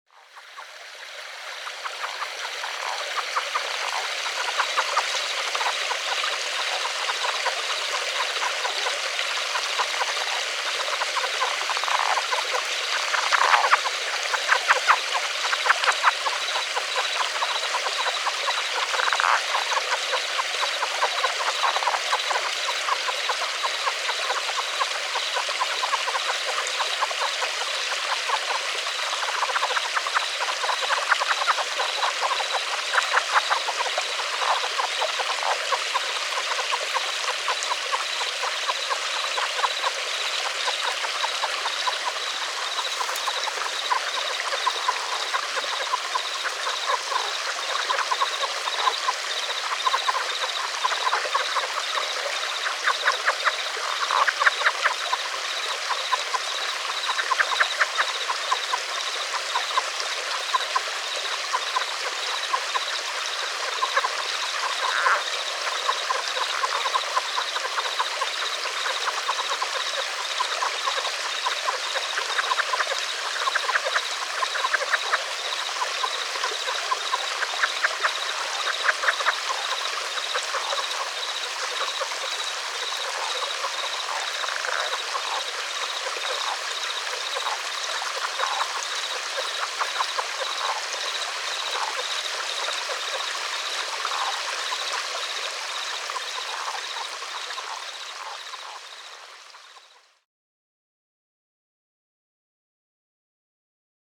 Listen to the advertisement calls of a group of Relict Leopard Frogs.
Recorded at the Lake Mead National Recreation Area 3/20/08.